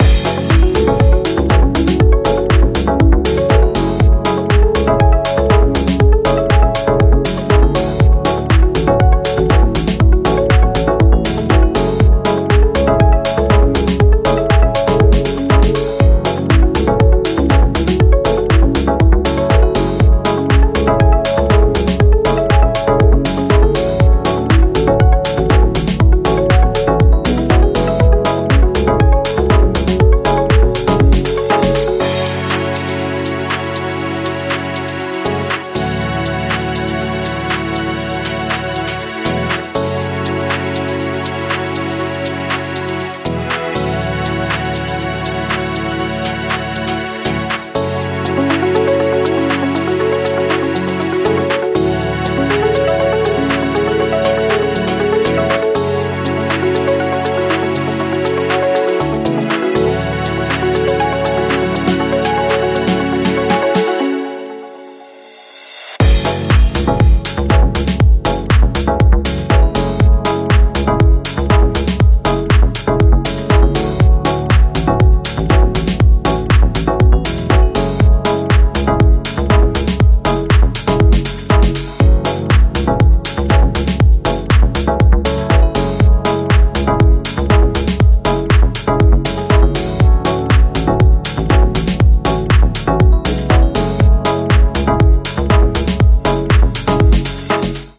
ここでも、今までのように程よくエレクトリックな音色を用いながらモダンでウォームなディープ・ハウスを展開。